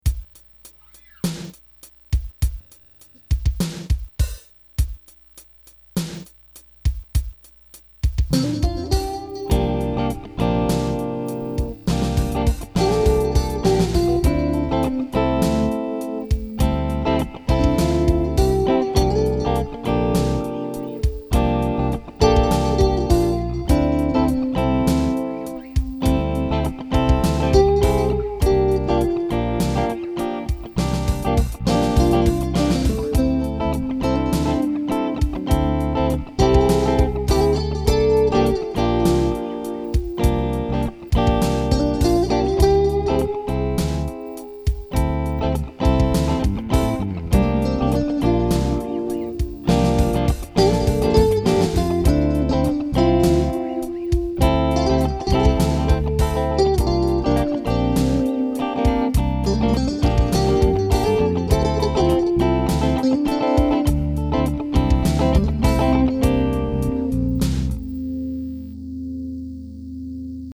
Batería: Pedal MultiFx Zoom
Bajo: Yo
Guitarra: Yo
intentando_jazz.mp3